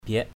/biaʔ/